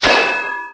metalcap.ogg